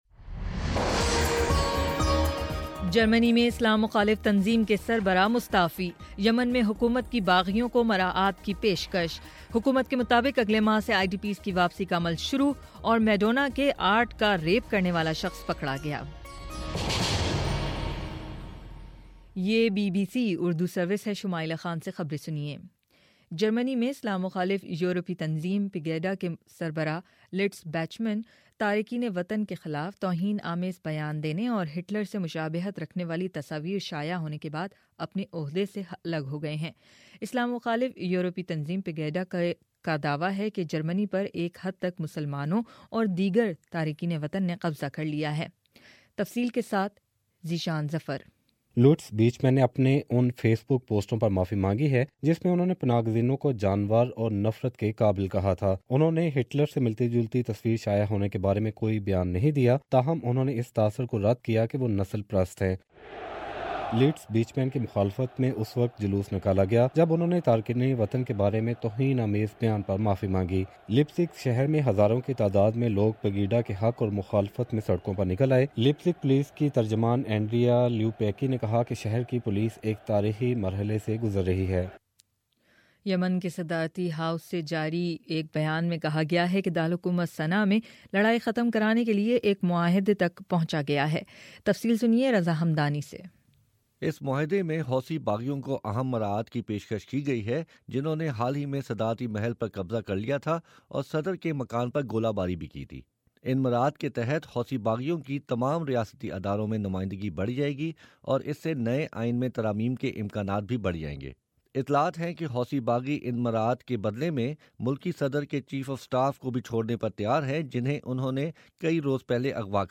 جنوری 22: صبح نو بجے کا نیوز بُلیٹن